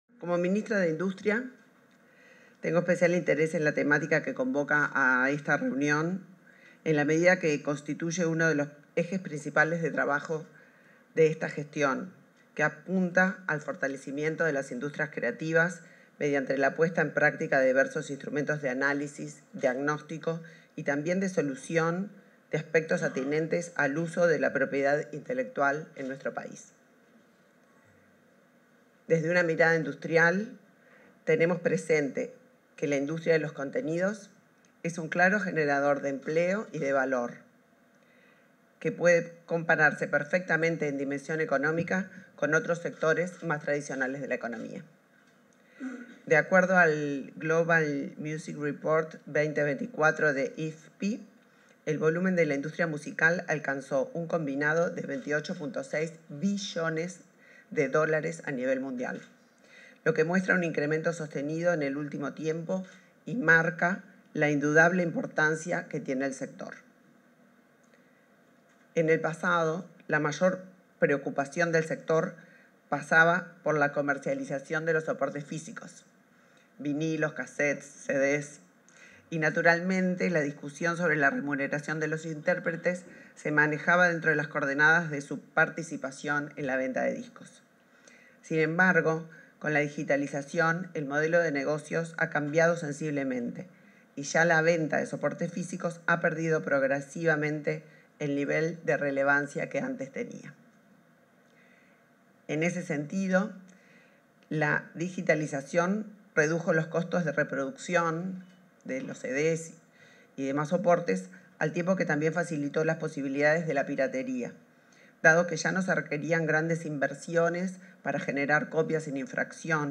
Palabras de la ministra de Industria, Energía y Minería, Elisa Facio
En el marco de la Conferencia Internacional sobre Derechos de Intérpretes en el Entorno Digital, este 21 de junio, se expresó la ministra de Industria